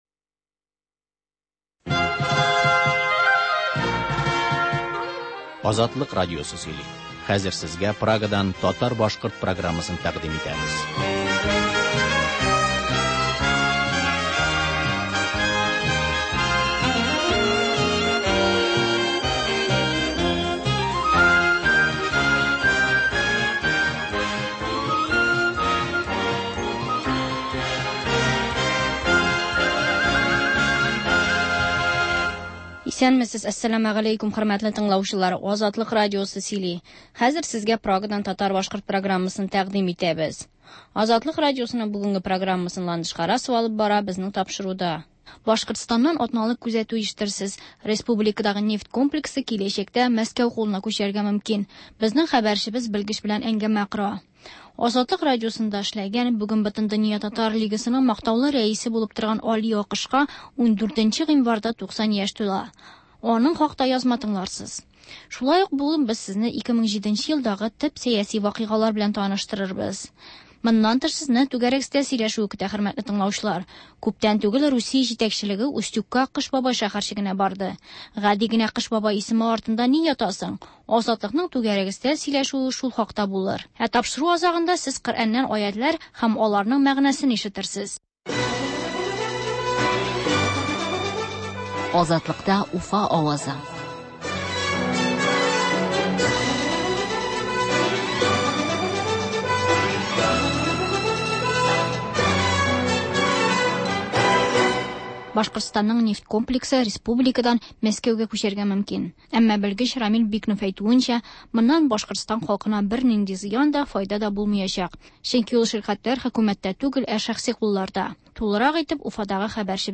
сәгать тулы хәбәр - Башкортстаннан атналык күзәтү - түгәрәк өстәл артында сөйләшү